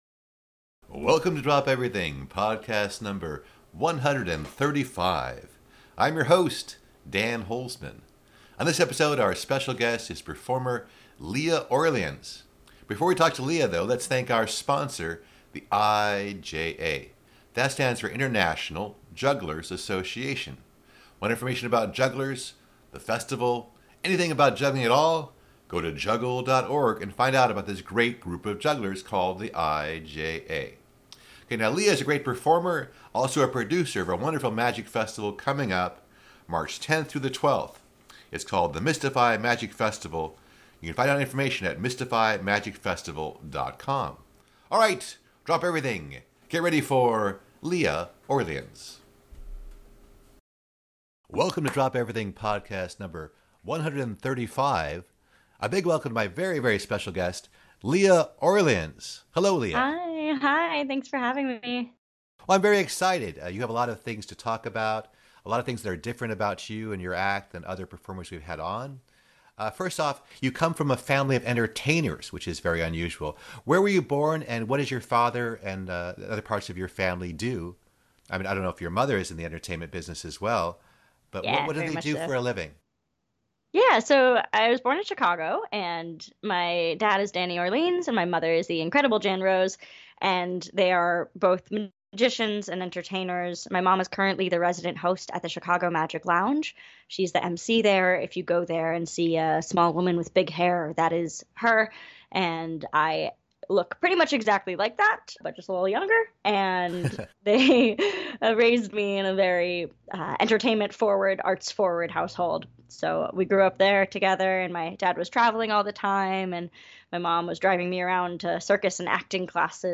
interviews juggling personalities.